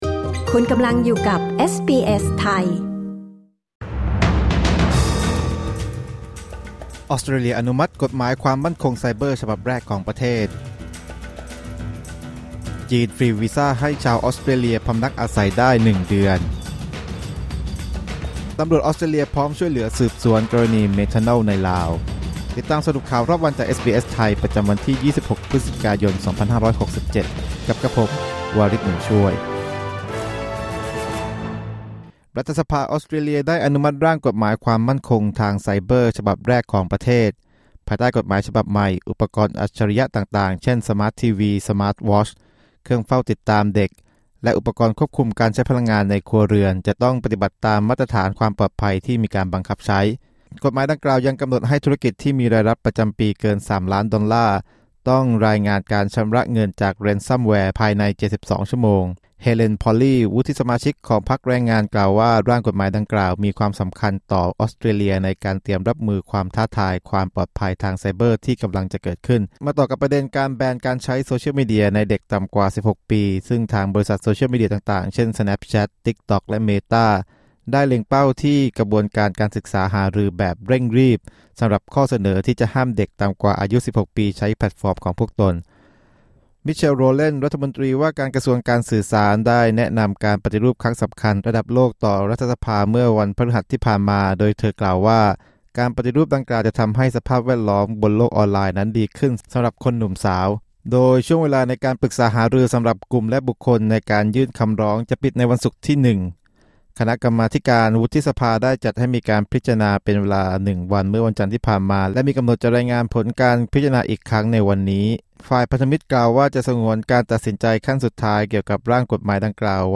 สรุปข่าวรอบวัน 26 พฤศจิกายน 2567